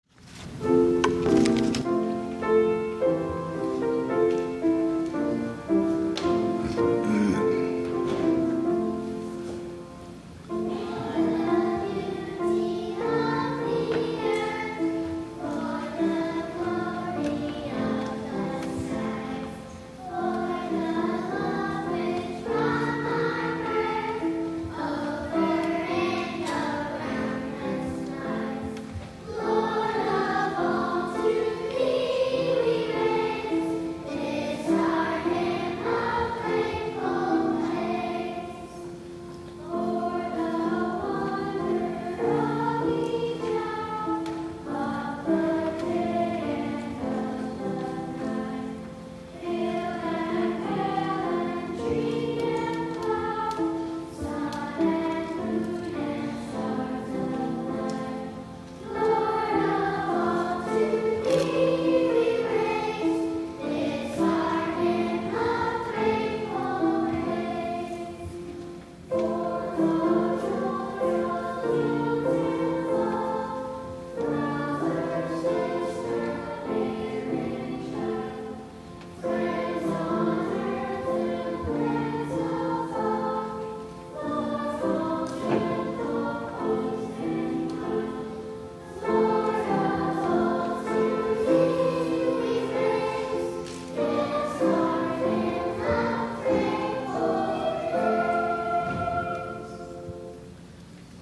11 A.M. WORSHIP
THE CHORAL RESPONSE